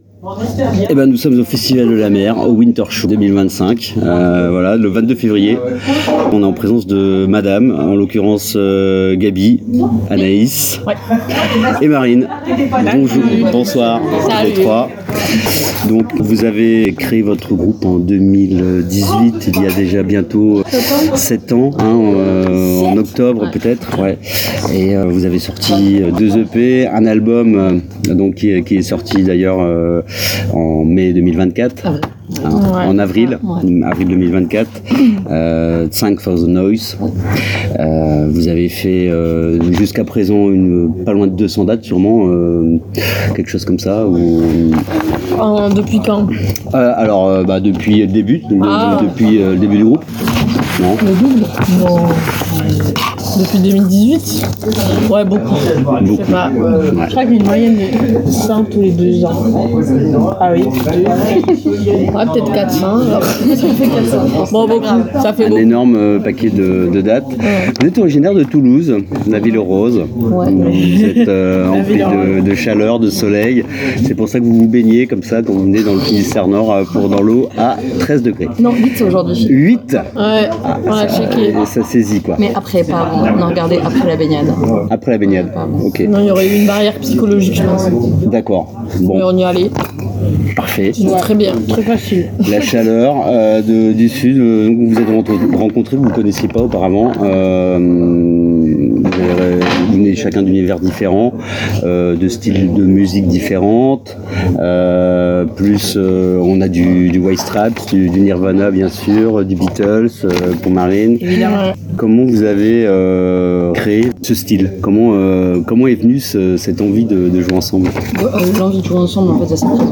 Interview MADAM FDLM Winter Show 2025 - DzFishRadio
Ce samedi 22 février 2025 à Landunvez se produisait entres autres le groupe de rock Toulousain MADAM qui nous a accordé cette interview avant leur (…)